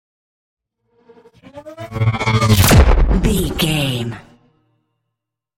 Pass by fast vehicle engine explosion sci fi
Sound Effects
Fast
futuristic
intense
pass by